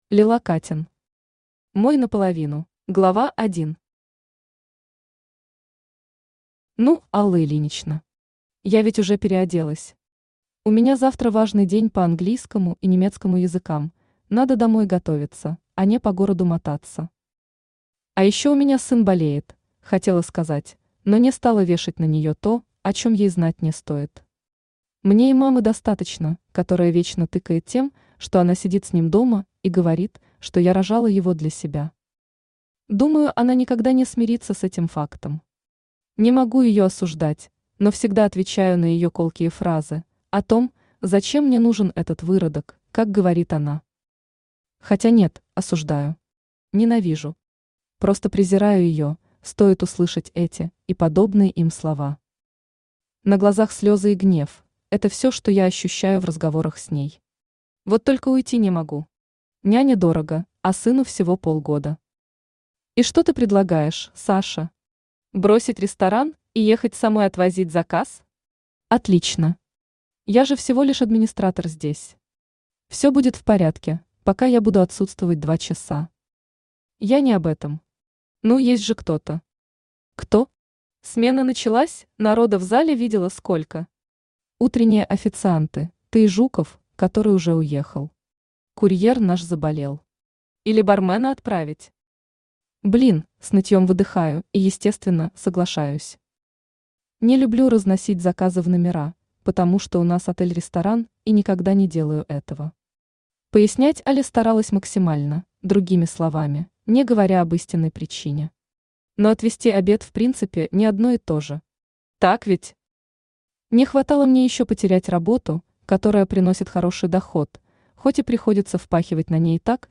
Aудиокнига Мой наполовину Автор Лила Каттен Читает аудиокнигу Авточтец ЛитРес.